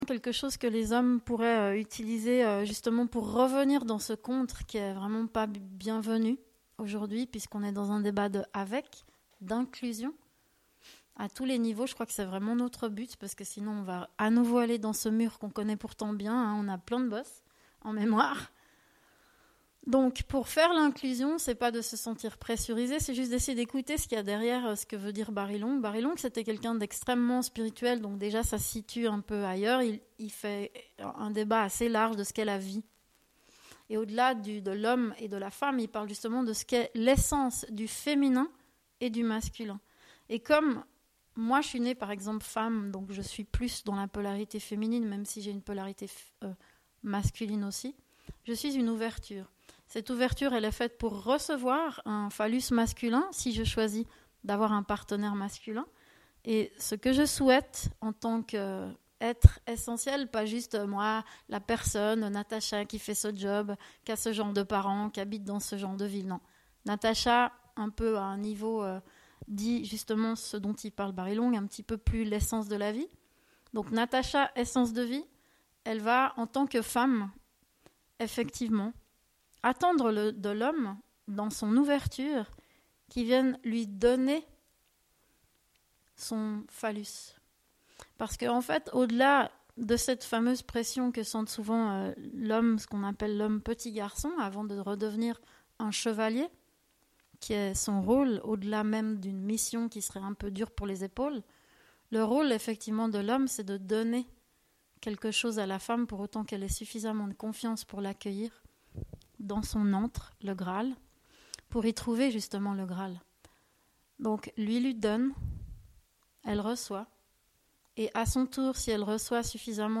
2ème partie de l'emission-discussion sur le feminisme diffusée live le 12 Novembre